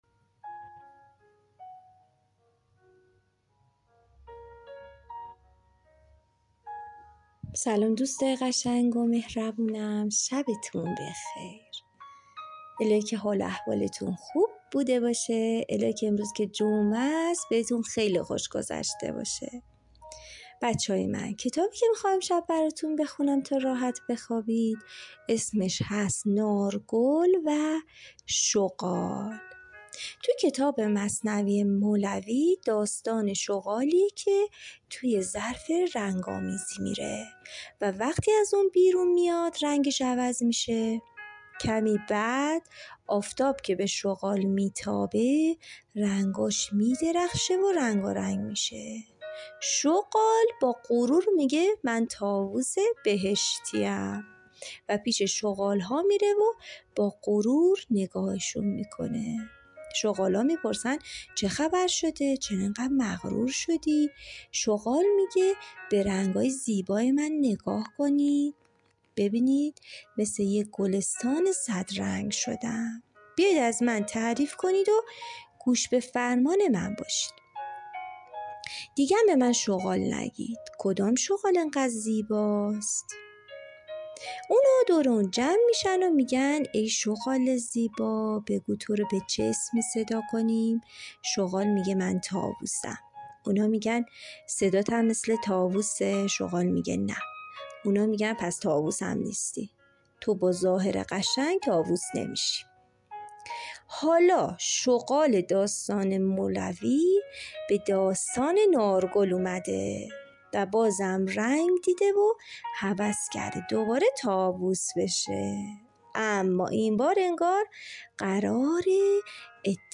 قصه صوتی کودکانه